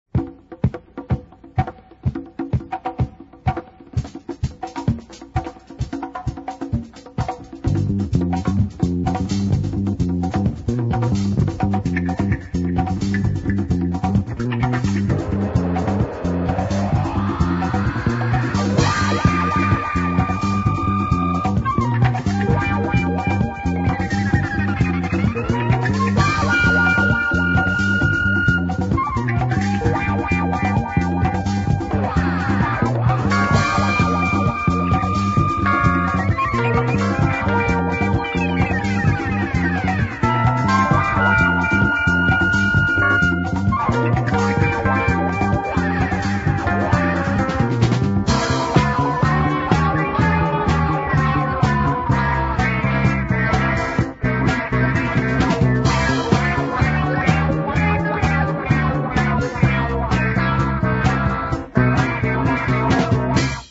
Choppy wah and flute make this track a winner.